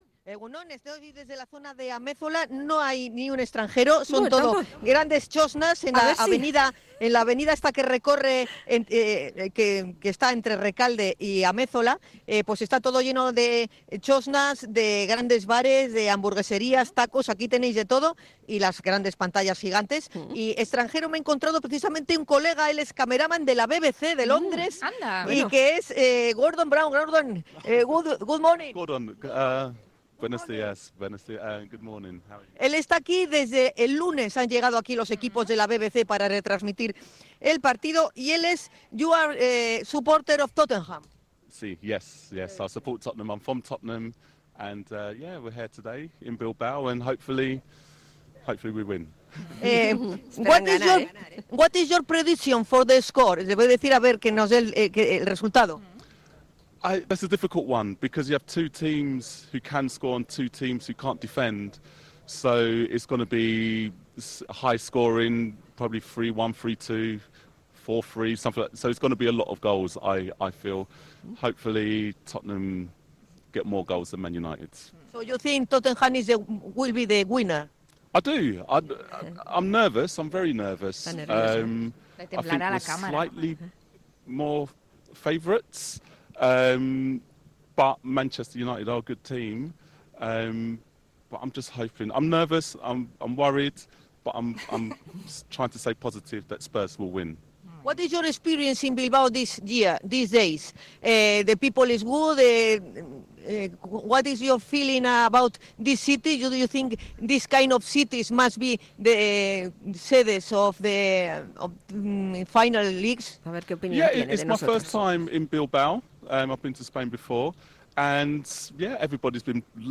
Más de 50.000 ingleses están ya en la villa para disfrutar de la final de San Mamés que va a enfrentar a Tottenham y Manchester United. A las 10 de la mañana se ha inaugurado la fan zone de Ametzola donde se ubican los hinchas de los Spurs.